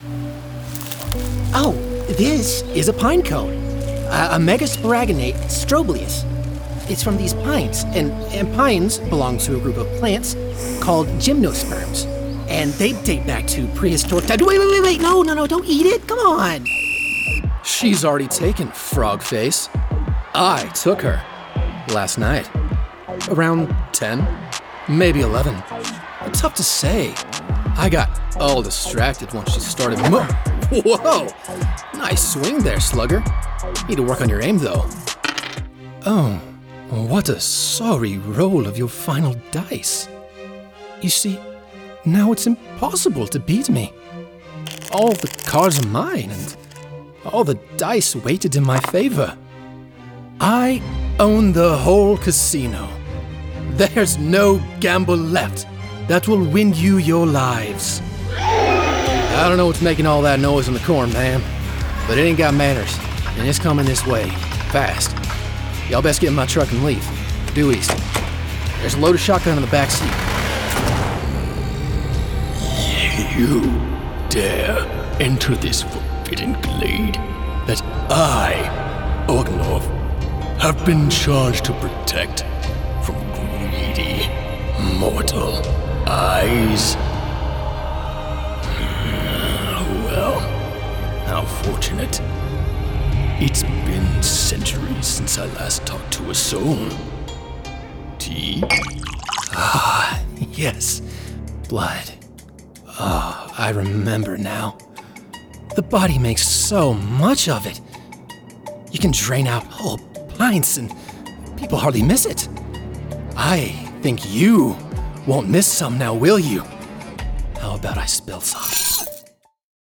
2021 Character Demo